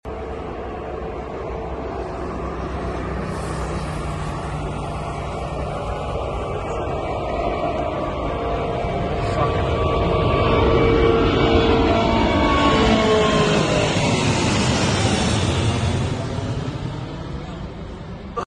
Pure 787 Engine sound